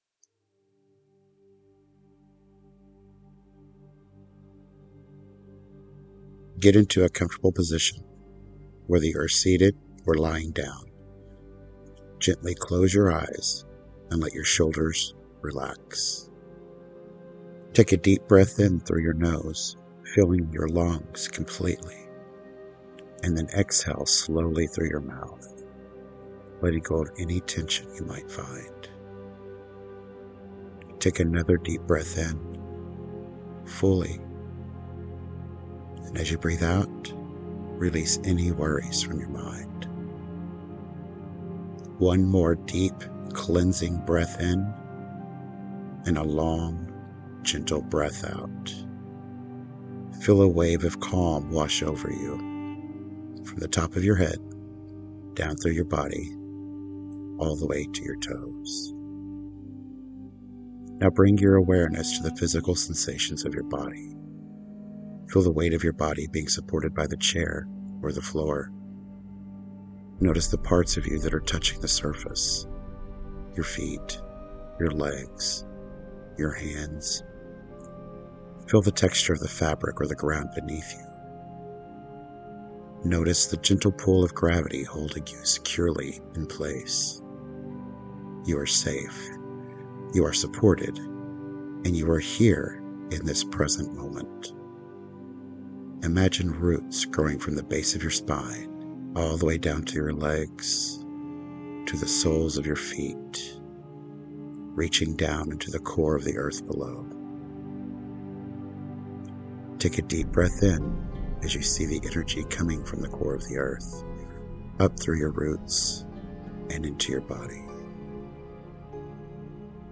Extra Blog-Only Bonus: Your Guided Isis Meditation